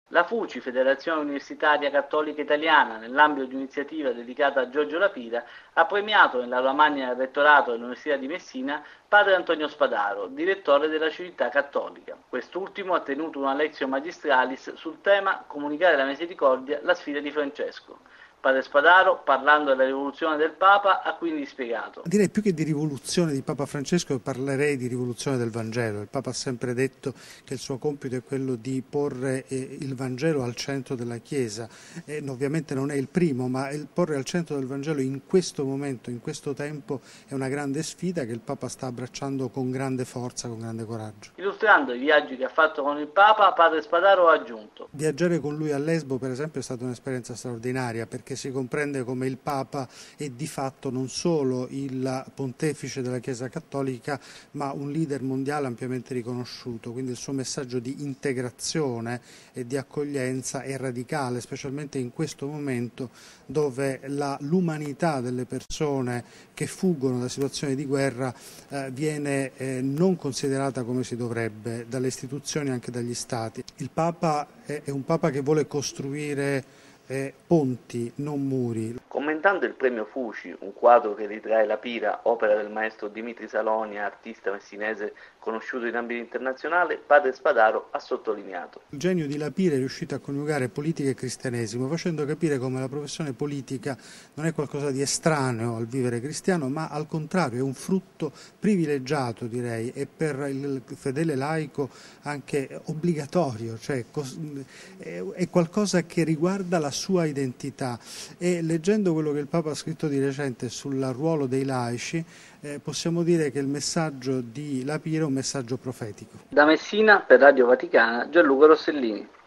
L’iniziativa, alla sua prima edizione, è della Fuci. Il servizio